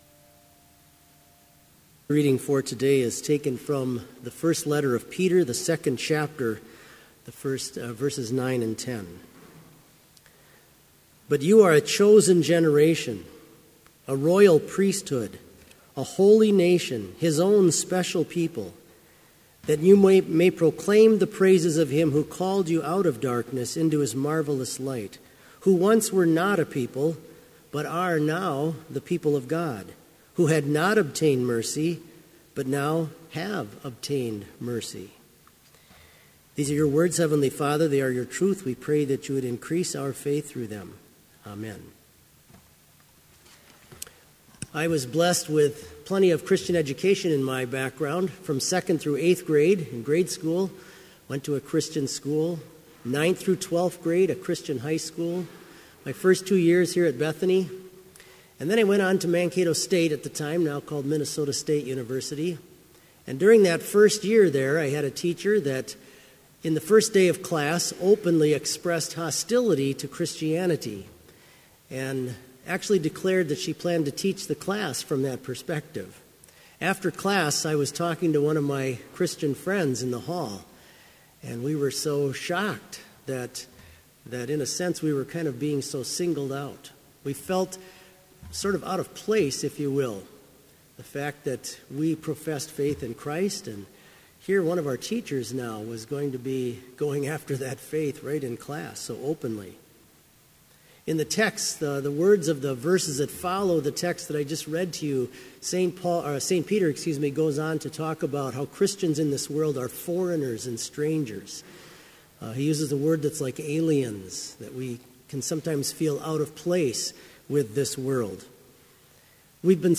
Complete service audio for Chapel - November 4, 2016